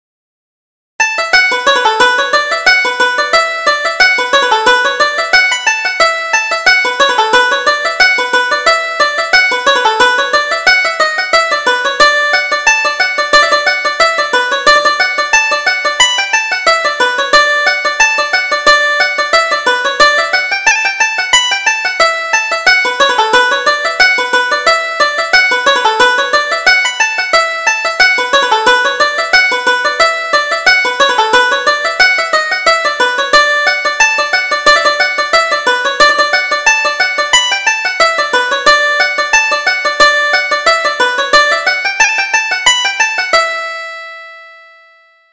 Reel: Pick Your Partner